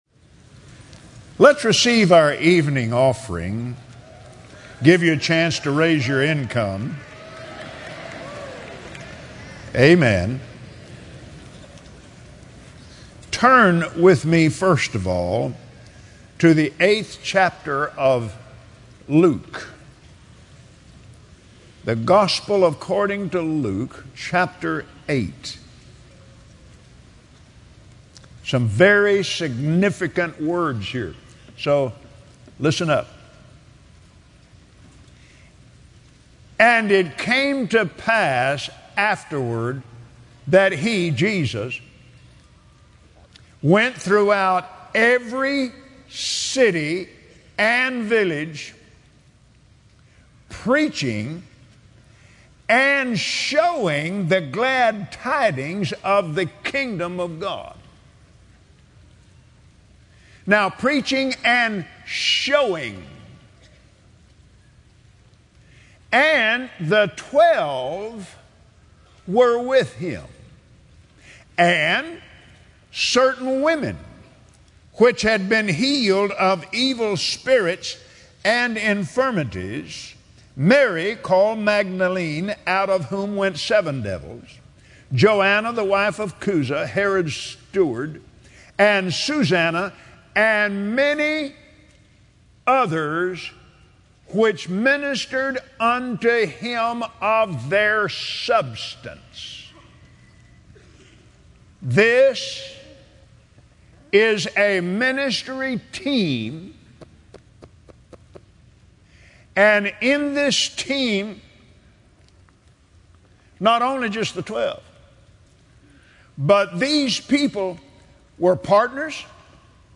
2017 Southwest Believers’ Convention: Monday Evening Offering Message (7:00 p.m.)